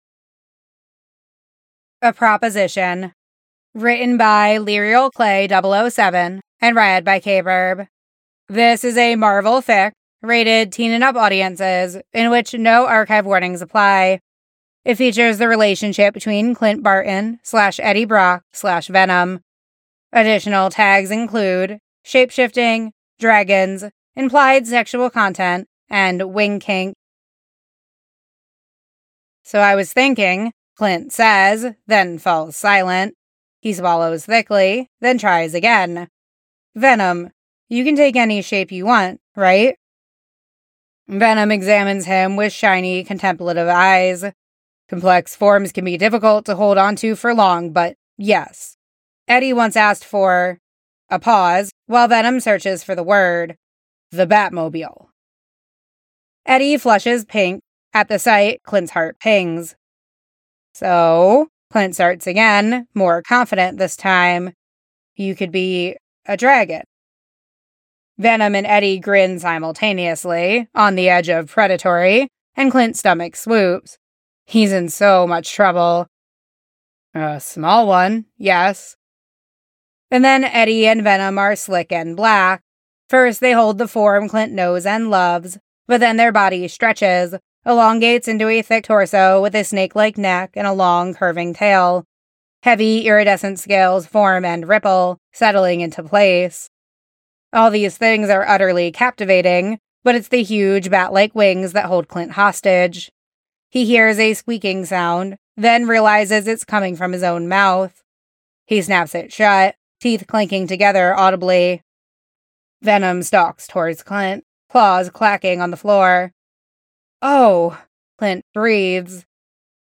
SFX on Venom's voice